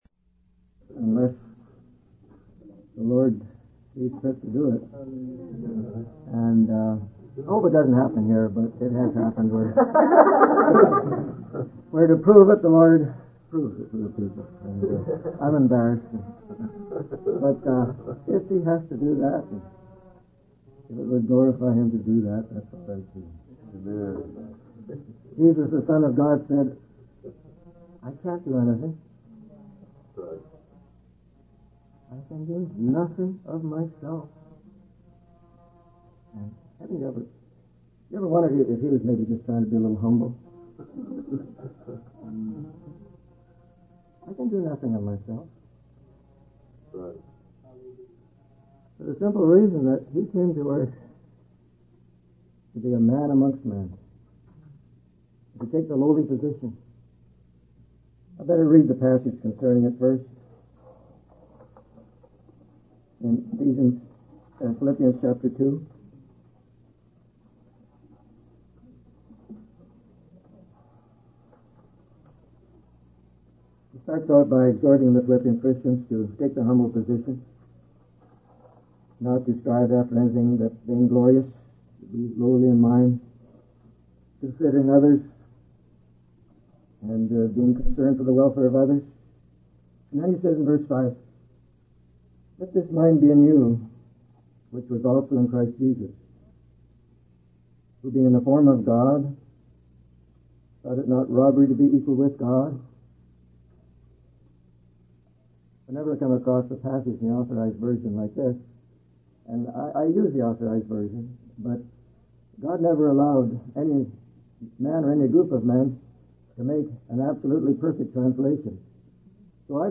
In this sermon, the speaker emphasizes the presence of a faithful and obedient people throughout church history who have been able to overcome the powers of darkness and fulfill God's purposes.